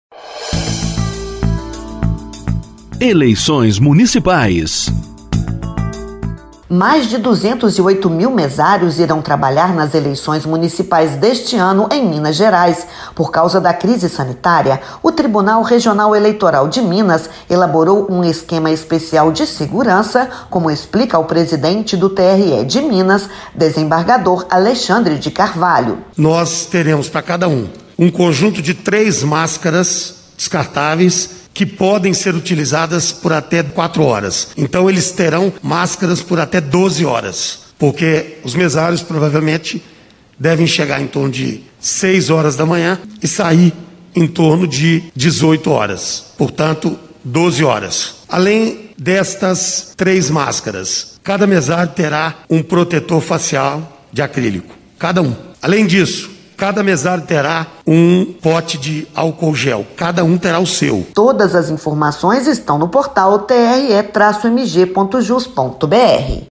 Ouça a explicação do presidente do Tribunal Regional Eleitoral em Minas, desembargador Alexandre de Carvalho, sobre as medidas de proteção para quem trabalhará na eleição.